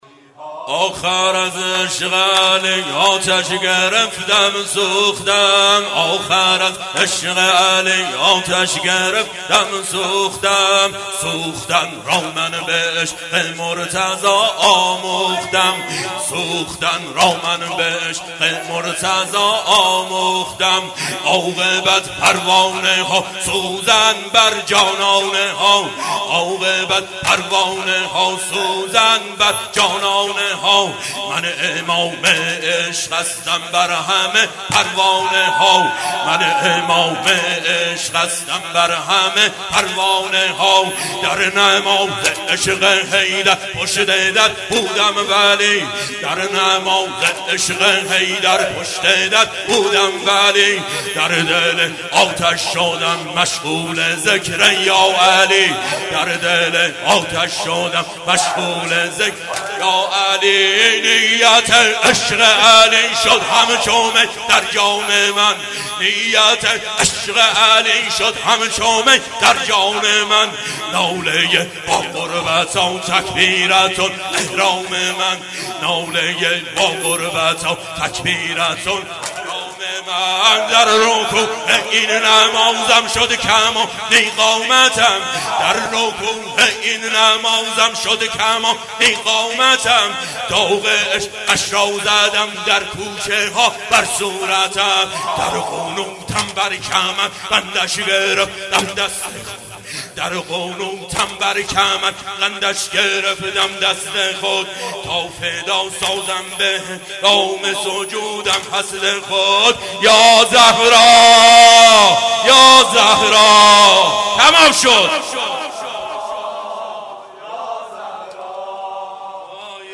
مراسم شب هفتم فاطمیه دوم 93/94(شب تحویل سال نو)
(شلاقی)